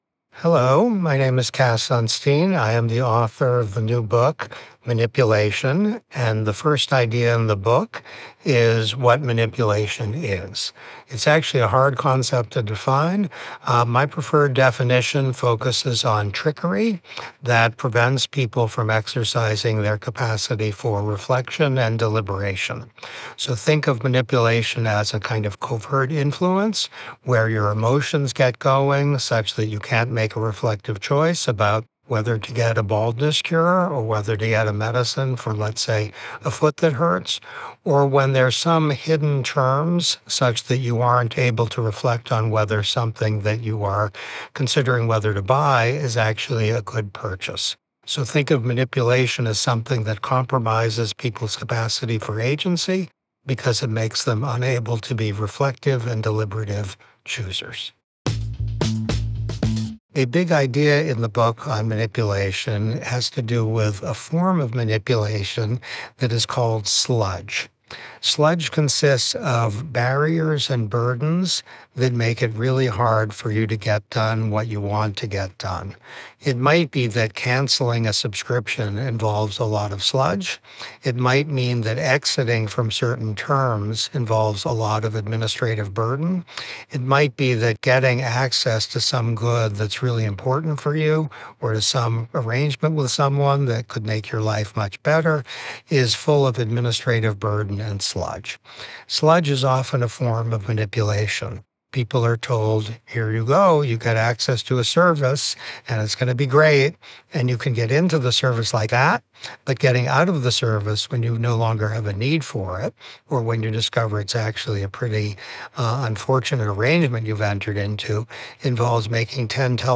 Below, Cass shares five key insights from his new book, Manipulation: What It Is, Why It’s Bad, and What to Do About It. Listen to the audio version—read by Cass himself—below, or in the Next Big Idea App.